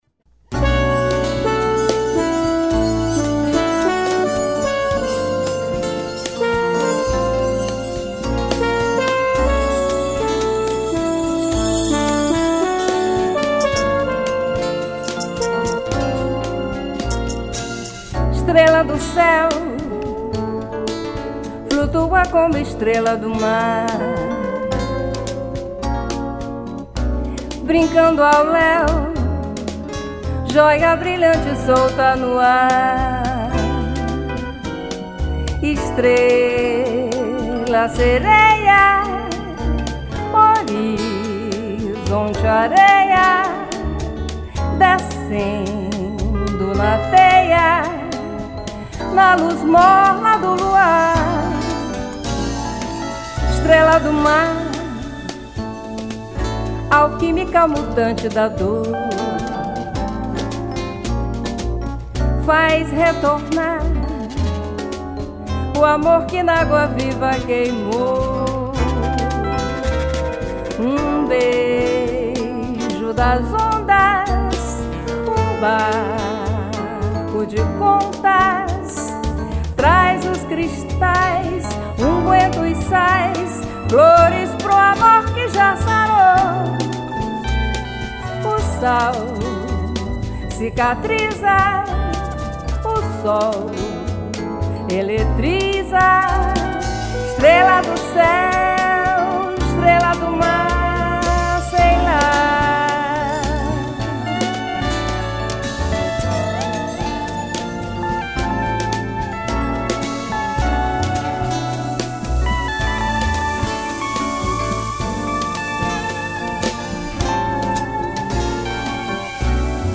184   03:55:00   Faixa:     Bossa nova
Percussão
Baixo Elétrico 6
Bateria
Piano Elétrico, Teclados
Violao Acústico 6